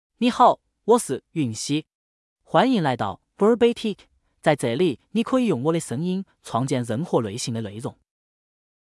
Yunxi — Male Chinese (Southwestern Mandarin, Simplified) AI Voice | TTS, Voice Cloning & Video | Verbatik AI
Yunxi is a male AI voice for Chinese (Southwestern Mandarin, Simplified).
Voice sample
Male
Yunxi delivers clear pronunciation with authentic Southwestern Mandarin, Simplified Chinese intonation, making your content sound professionally produced.